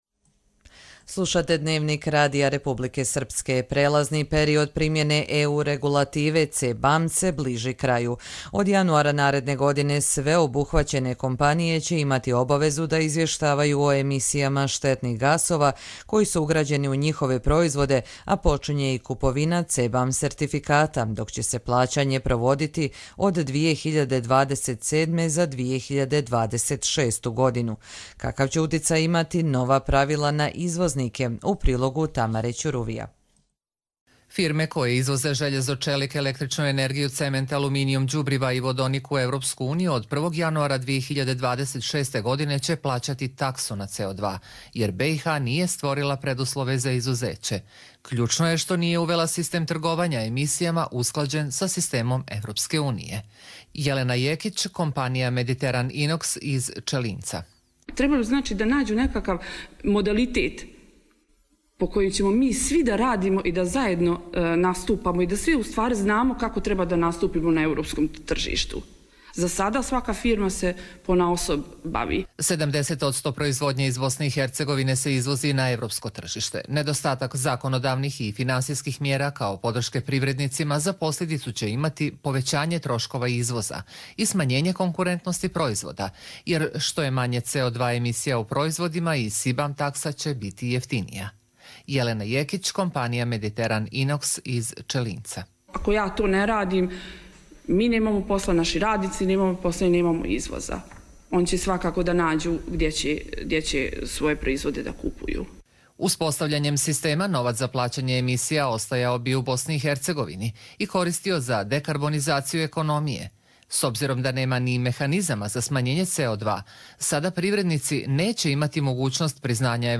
Радио репортажа